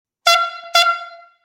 Buzinas a Ar para Bicicletas Mini
• 01 corneta;
• Intensidade sonora 130db;
• Acionamento através de bomba manual;
Som da Buzina